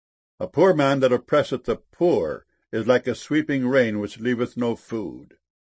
sweeping.mp3